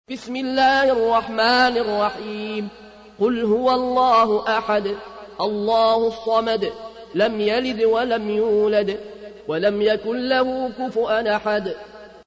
تحميل : 112. سورة الإخلاص / القارئ العيون الكوشي / القرآن الكريم / موقع يا حسين